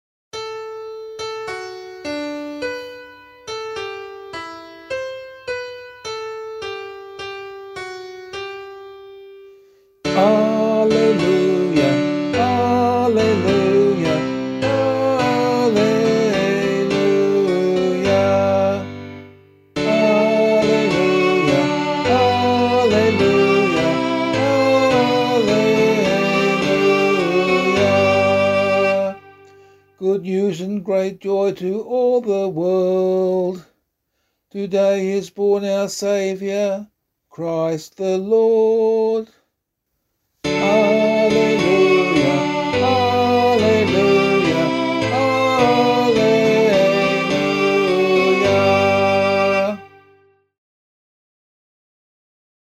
Gospel Acclamation for Australian Catholic liturgy.
006 Christmas Gospel 1 [LiturgyShare B - Oz] - vocal.mp3